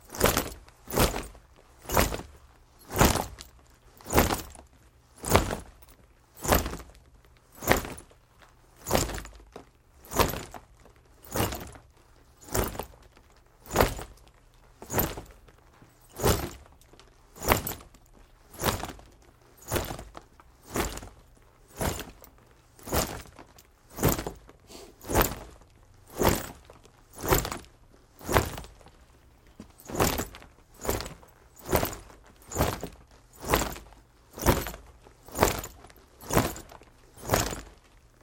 Звук тяжелых шагов в бронежилете для монтажа